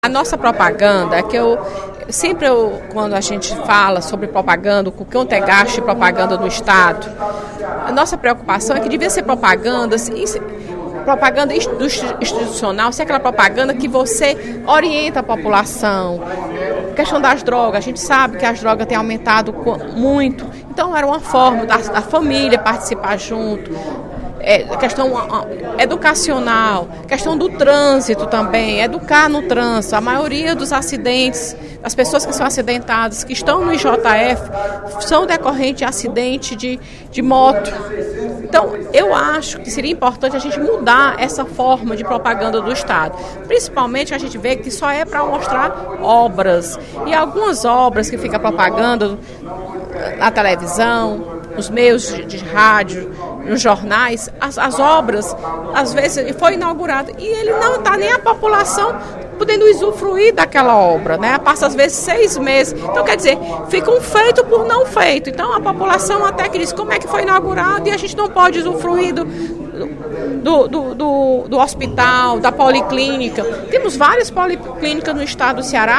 A deputada Fernanda Pessoa (PR) fez um apelo nesta terça-feira (05/03), durante o primeiro expediente da sessão plenária, para que o Governo do Estado utilize as verbas de publicidade em campanhas educacionais.